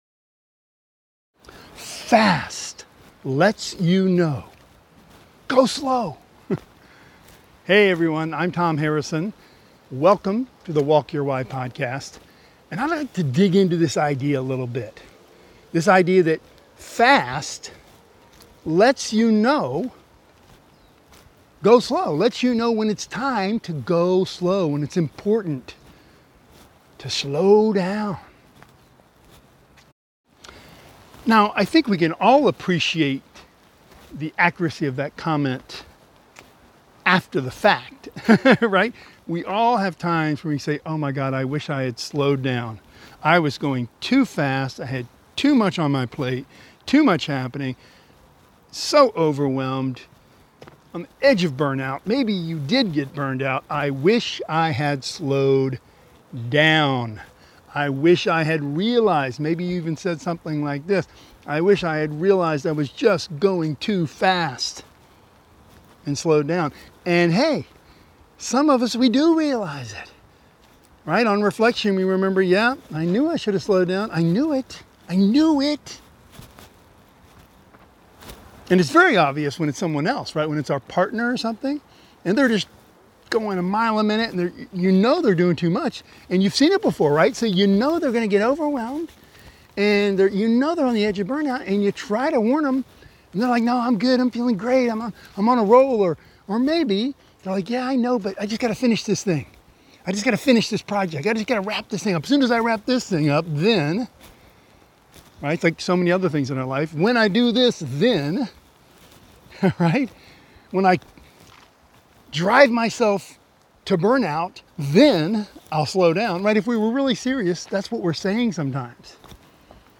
SLOW meditations